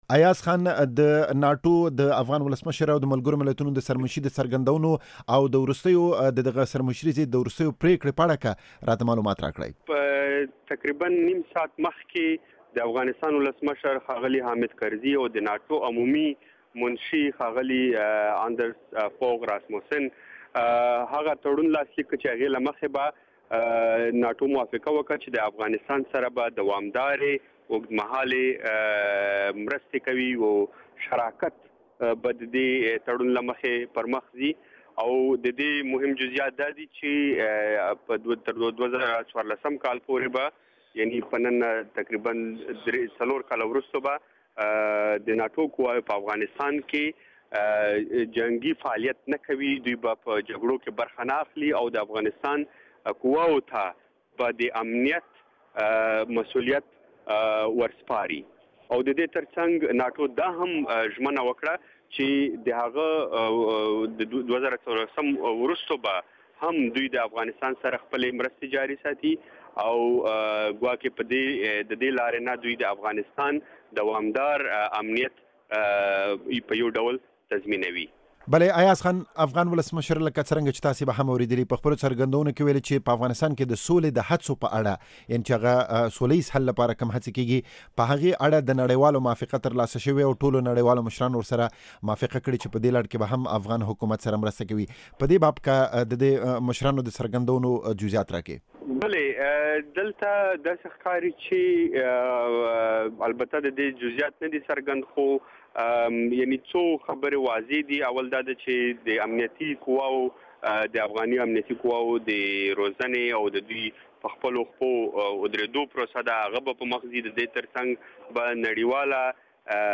مرکه واورۍ